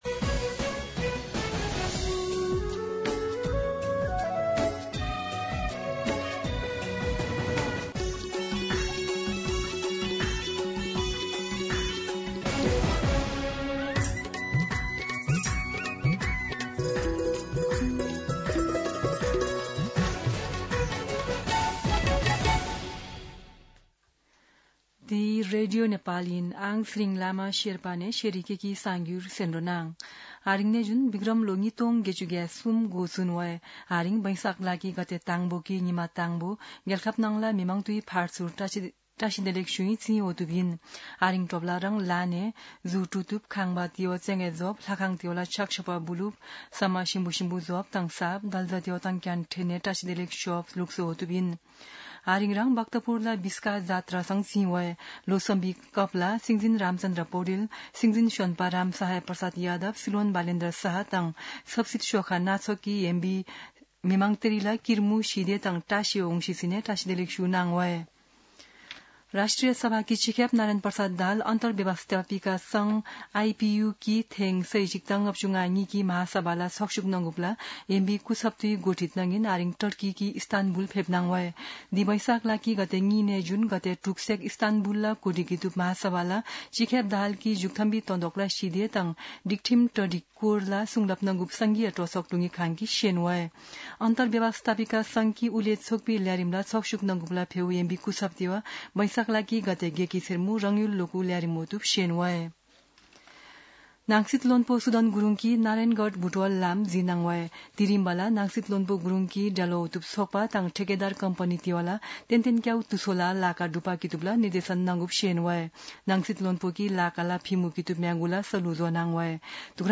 शेर्पा भाषाको समाचार : १ वैशाख , २०८३
Sherpa-News-01.mp3